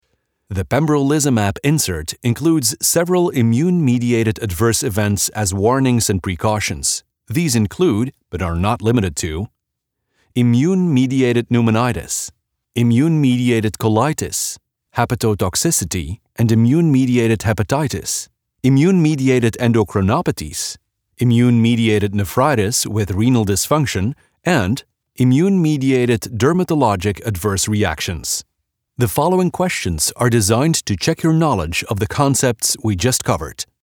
English Medical eLearning
Middle Aged
His sound? Modern, smooth, and unmistakably human.